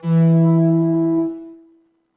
描述：通过Behringer调音台采样到MPC 1000。它听起来一点也不像风的部分，因此被称为Broken Wind。
Tag: 模拟 多样品 合成器 虚拟模拟